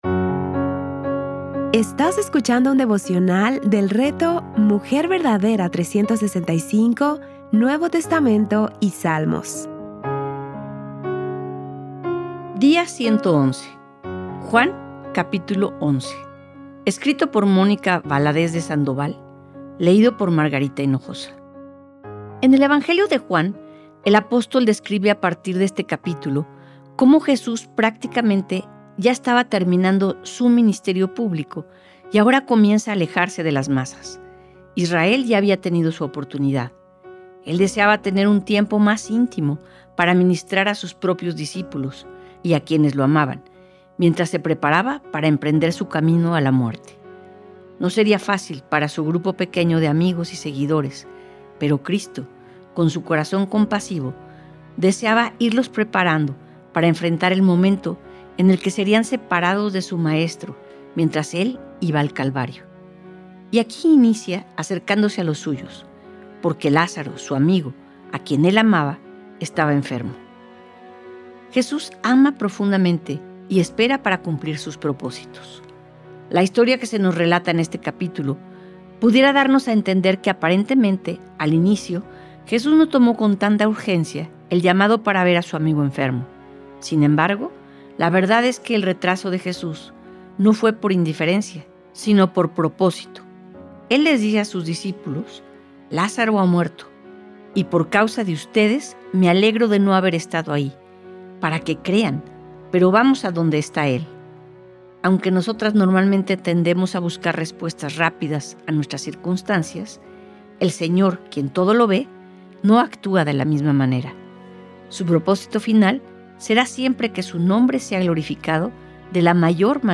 Series:  Juan y Salmos | Temas: Lectura Bíblica